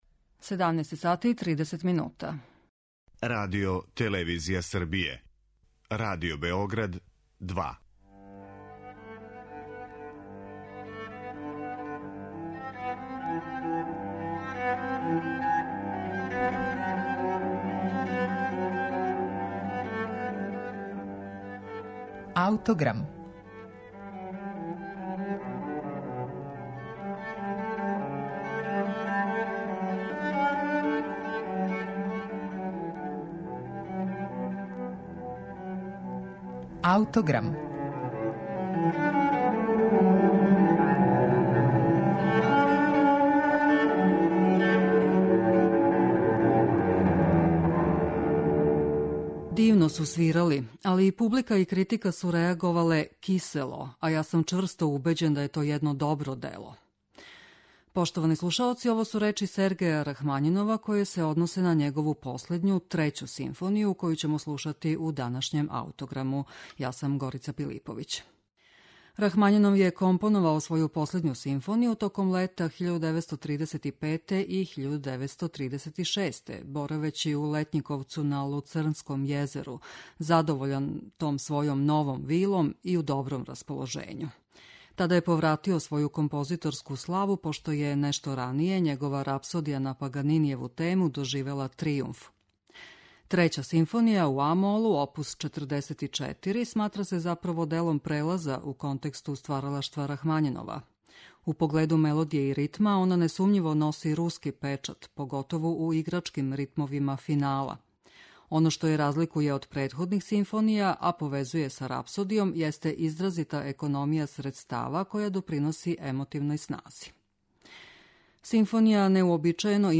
Трећа симфонија, у а-молу, оп. 44, сматра се, заправо, делом прелаза у контексту стваралаштва Рахмањинова. У погледу мелодије и ритма, она несумњиво носи руски печат, поготову у играчким ритмовима финала.